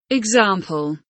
example kelimesinin anlamı, resimli anlatımı ve sesli okunuşu